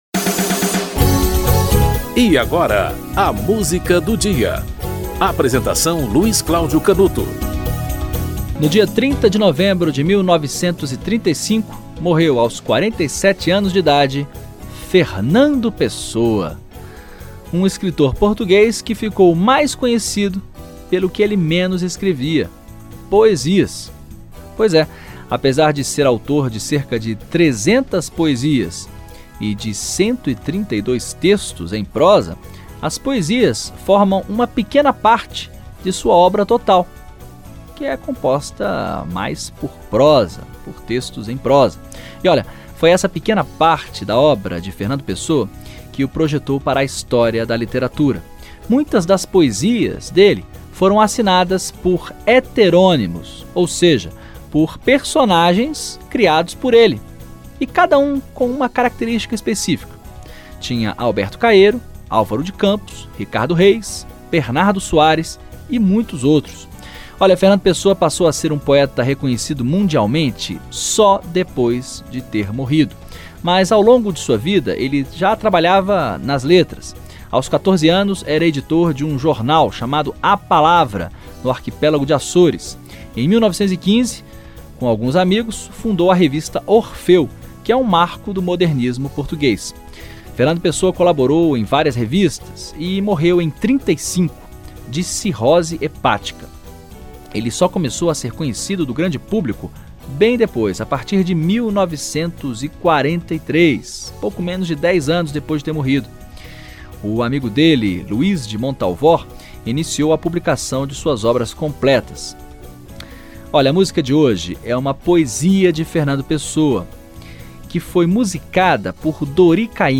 Dori Caymmi - Na Ribeira Deste Rio (Dori Caymmi, Fernando Pessoa)